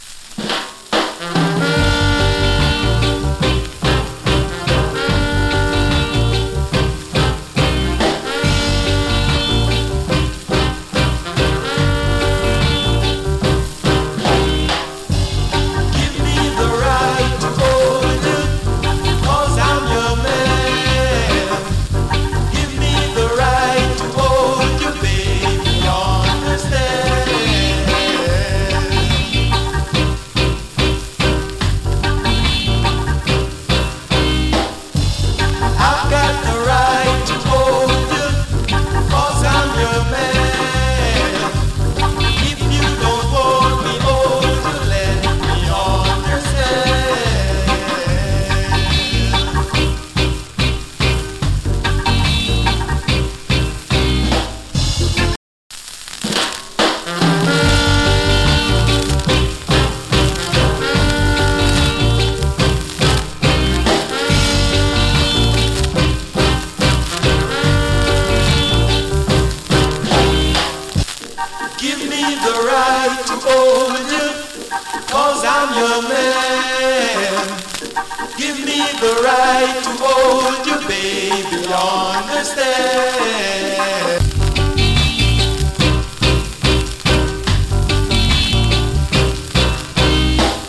＊SMALL HISS 有り。
チリ、パチノイズ少々有り。